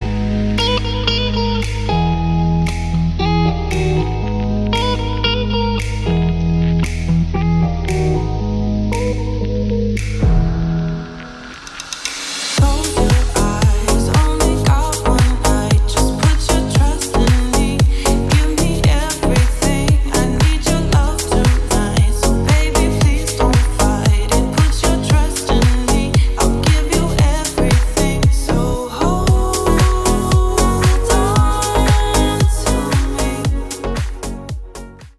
• Качество: 192, Stereo
deep house
красивая мелодия
Electronica
чувственные
красивый женский голос